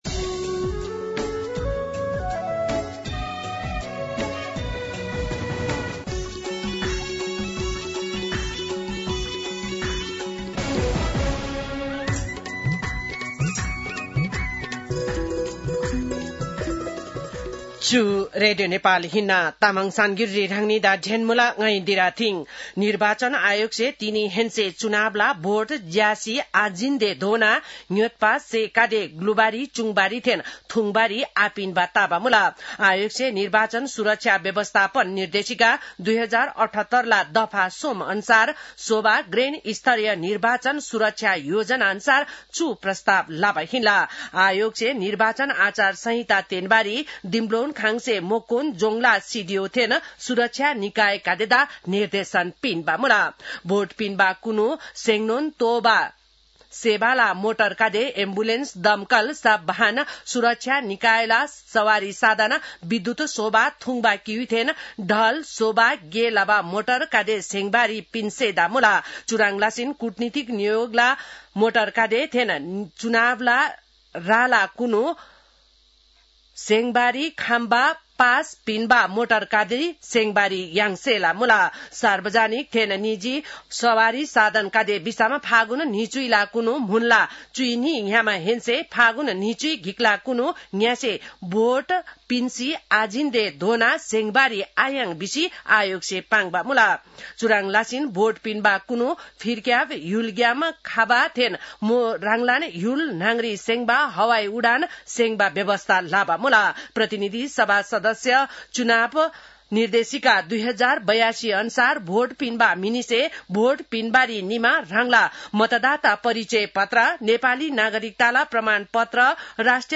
तामाङ भाषाको समाचार : १५ फागुन , २०८२